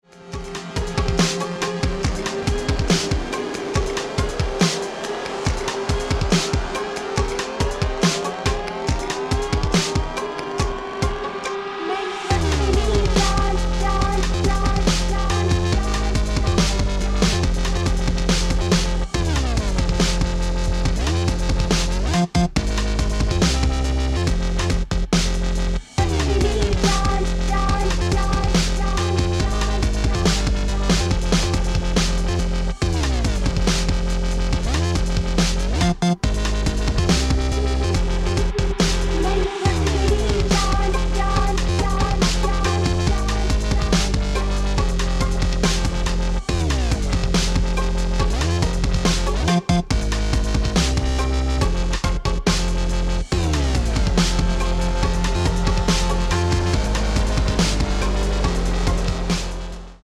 Armed with their unique home made beats and quick-fire mixes